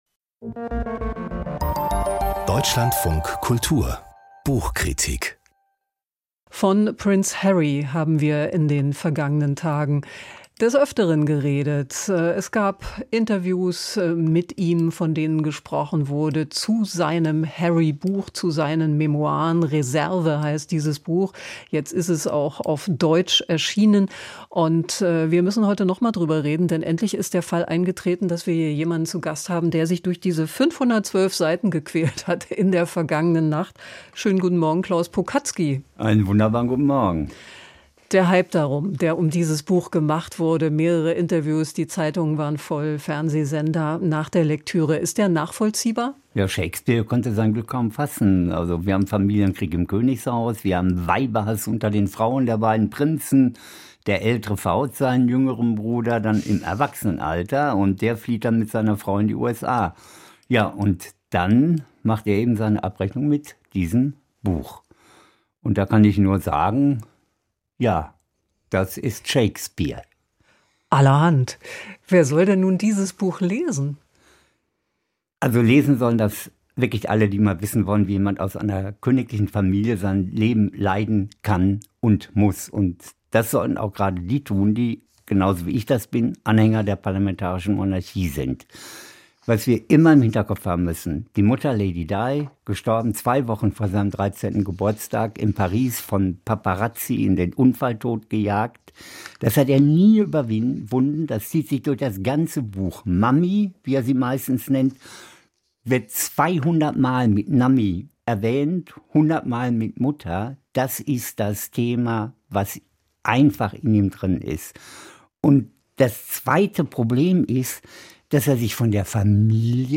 Aus dem Podcast Buchkritik